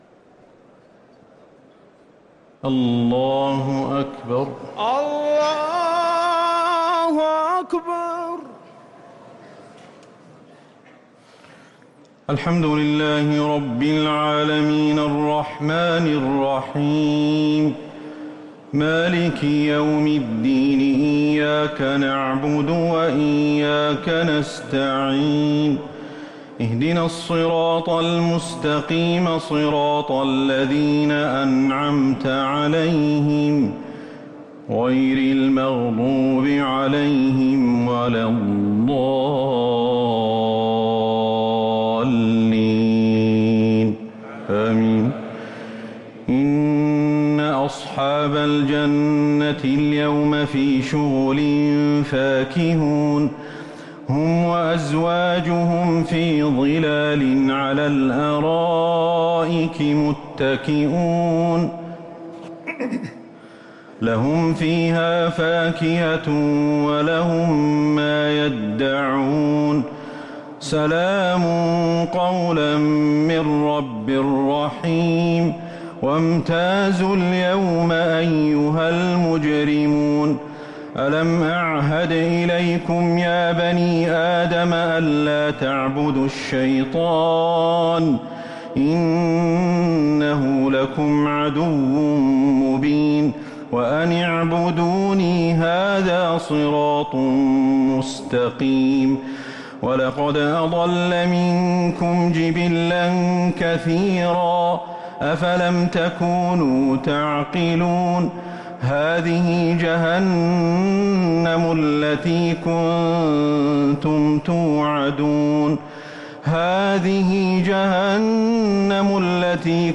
صلاة التراويح ليلة 25 رمضان 1444
التسليمتان الأخيرتان صلاة التراويح